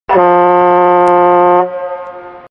دانلود صدای بوق کشتی 1 از ساعد نیوز با لینک مستقیم و کیفیت بالا
جلوه های صوتی